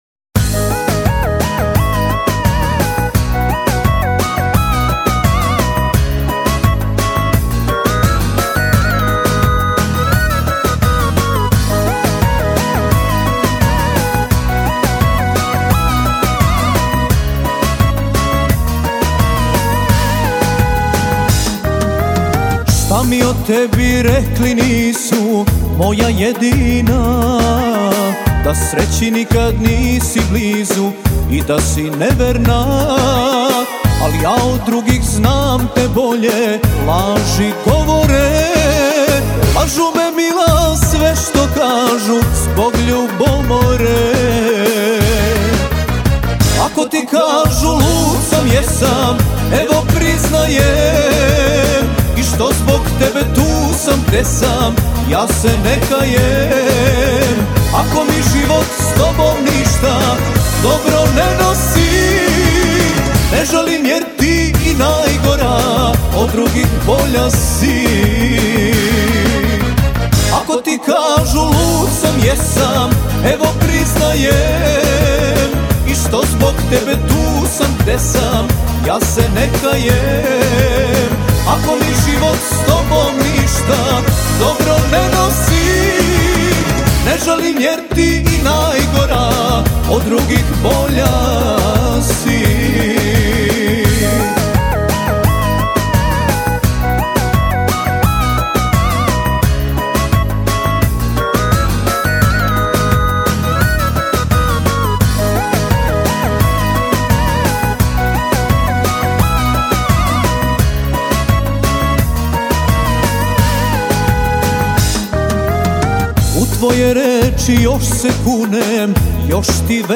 Для любителей современной сербской эстрадной музыки.